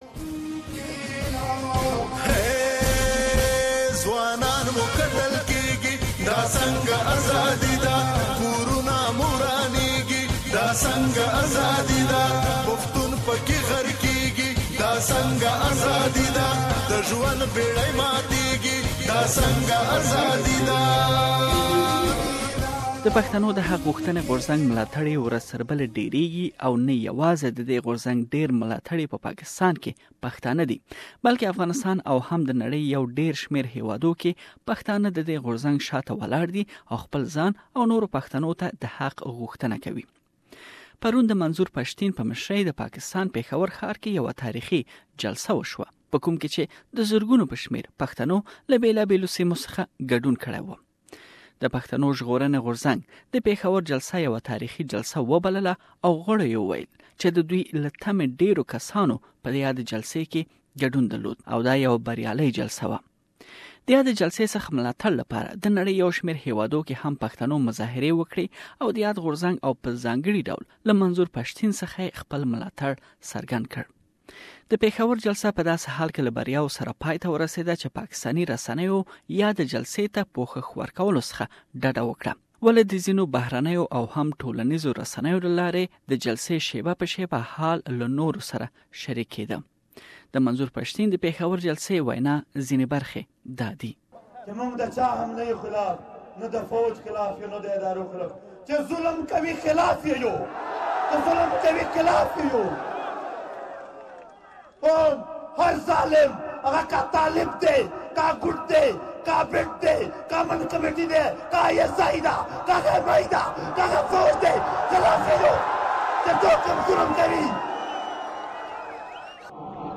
We have a report on Sydney protest that you can listen to the full report here.